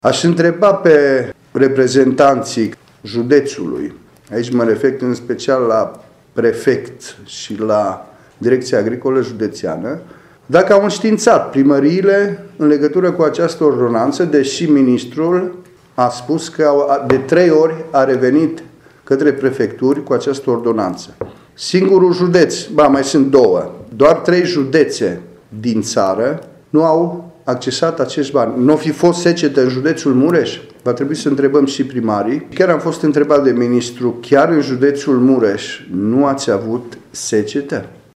Parlamentarul liberal a declarat astăzi, într-o conferință de presă, că Ministrul Agriculturii l-a sesizat că există câteva județe care nu au solicitat acordarea de despăgubiri pentru pagubele produse de secetă.